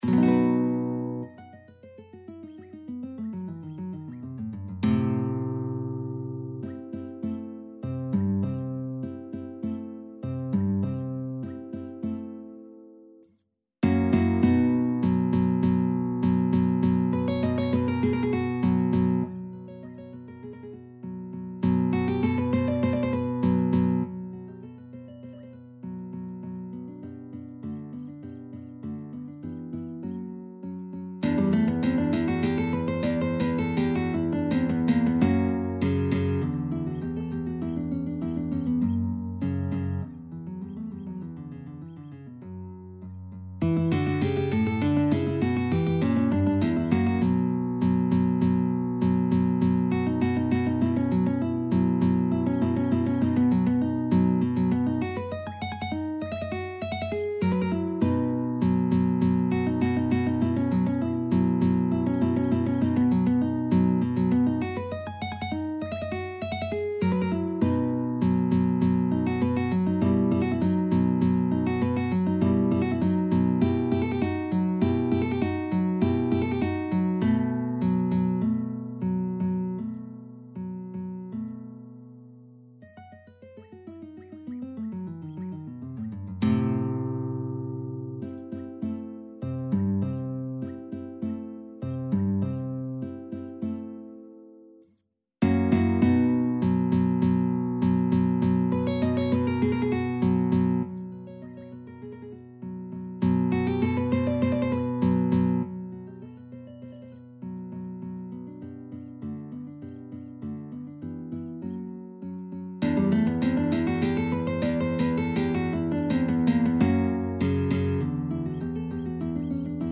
Free Sheet music for Guitar
Guitar  (View more Intermediate Guitar Music)
Classical (View more Classical Guitar Music)